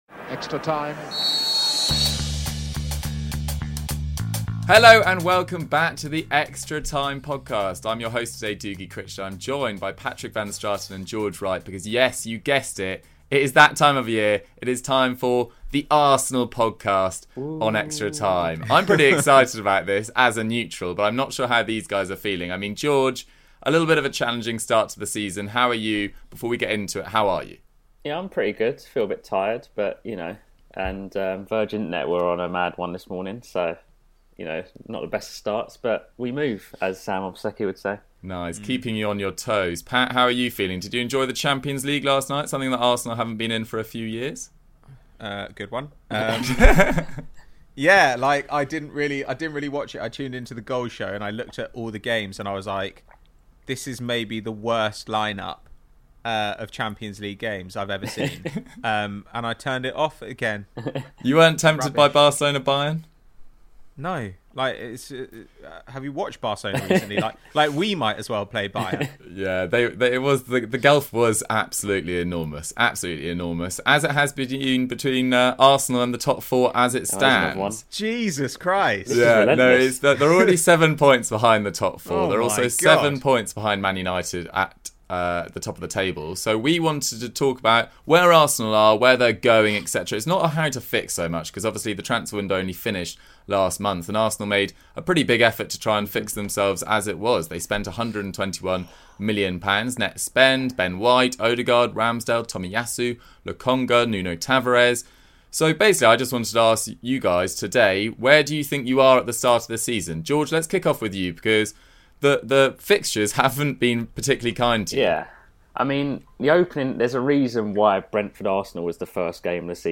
is joined today by two Arsenal fans